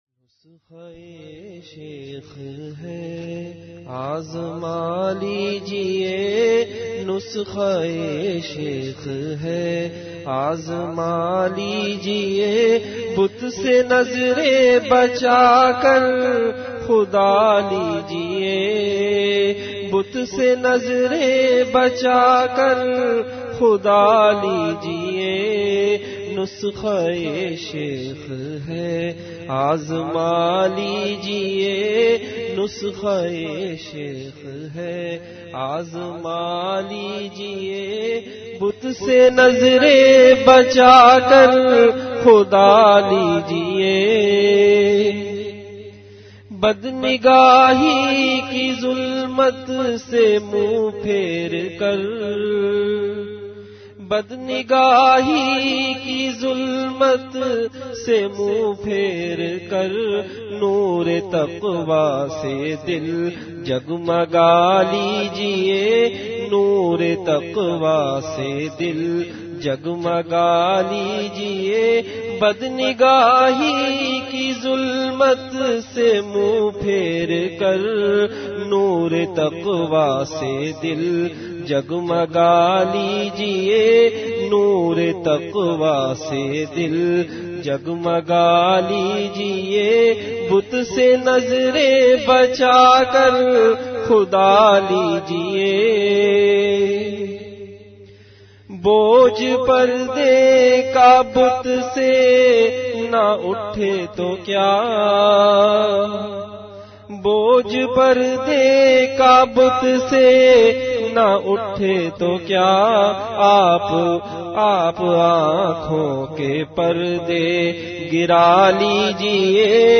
Majlis-e-Zikr · Home Zindagi Kay Marhalay